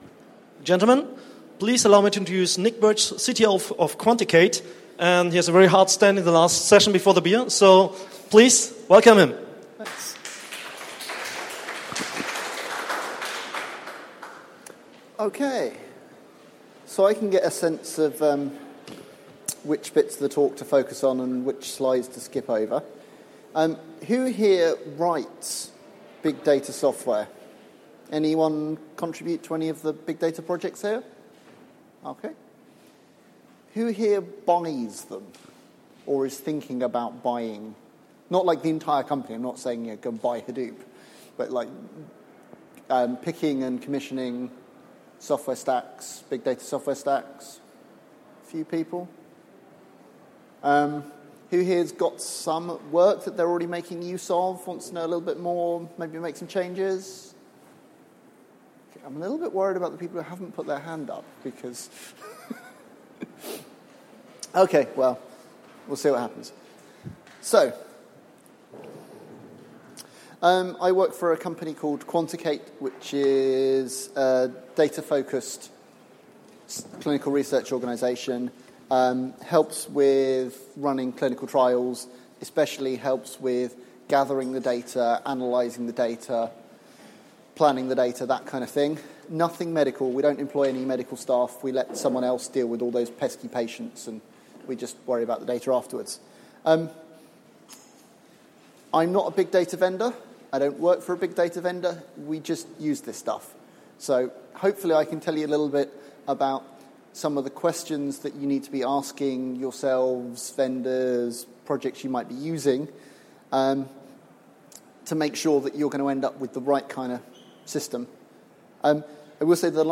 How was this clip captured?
Apache Big Data Seville 2016 – The Myth of the Big Data Silver Bullet – Why Requirements Still Matter